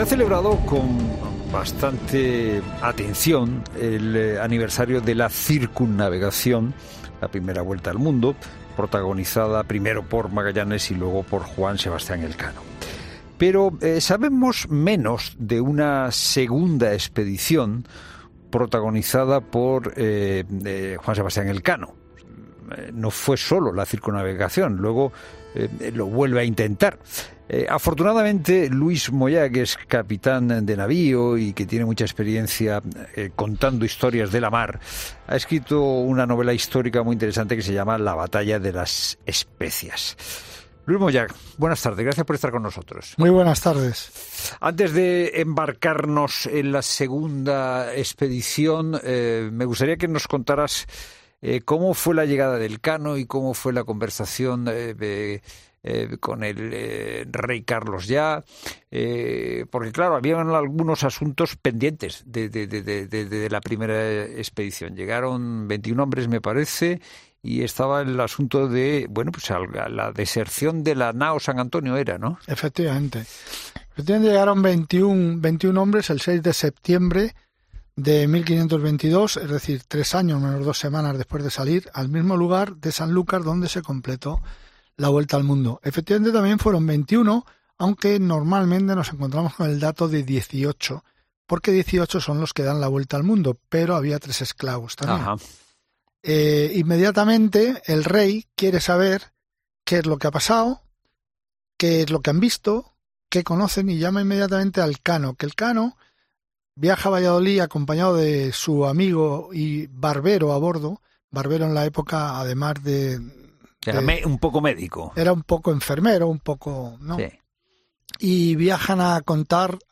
En 'La Tarde' de COPE hablamos con